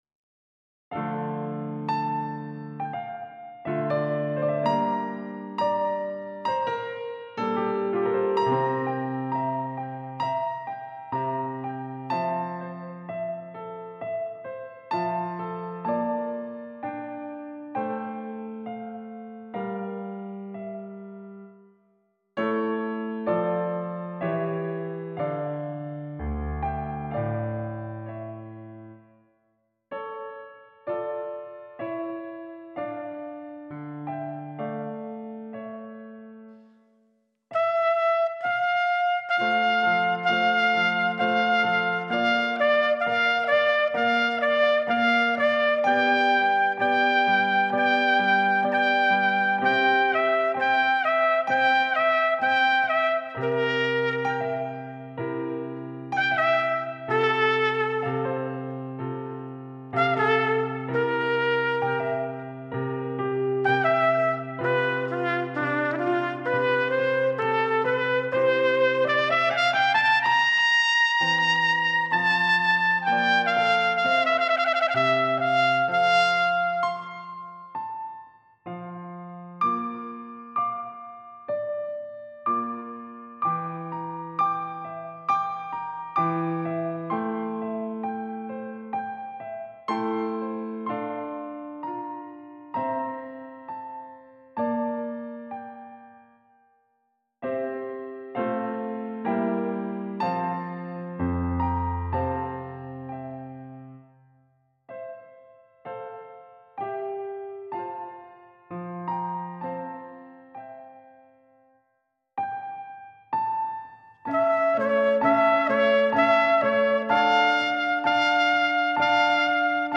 trumpeter